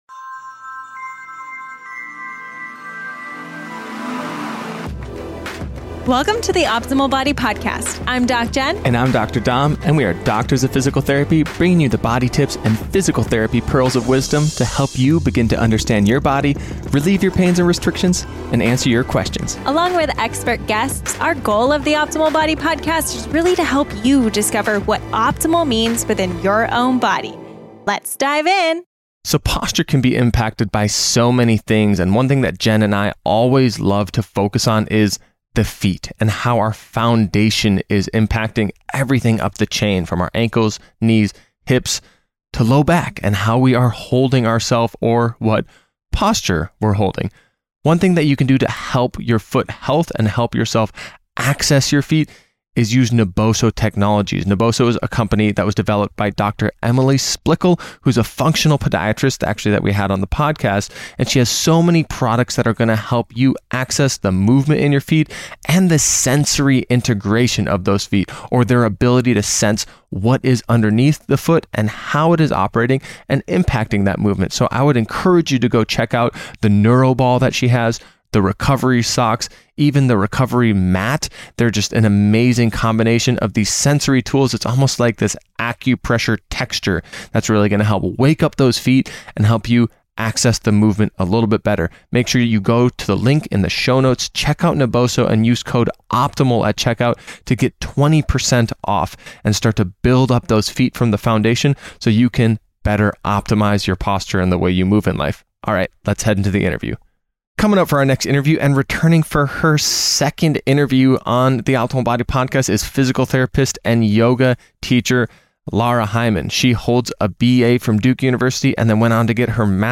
What You Will Learn in this Interview with : 3:36 - Does posture matter?